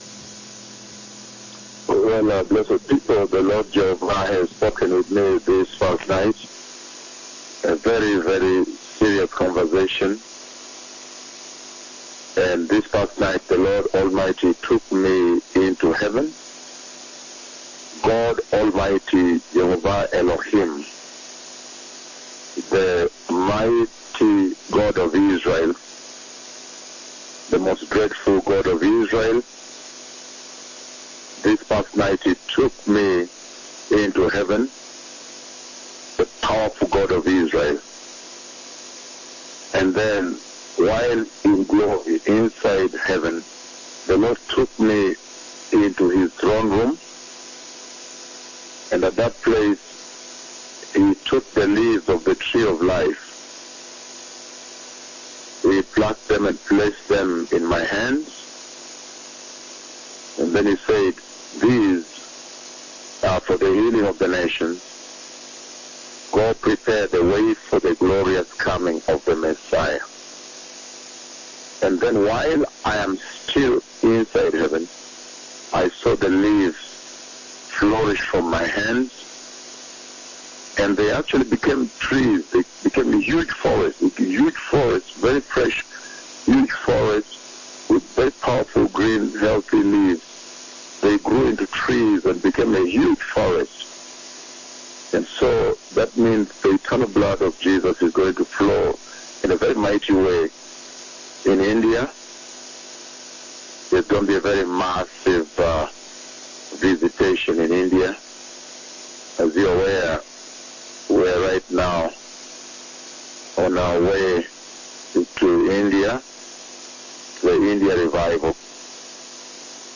PRÉSENTÉE PAR LES DEUX MÉGA PUISSANTS PROPHÈTES DE JÉHOVAH.
Diffusion capturée depuis JESUS IS LORD RADIO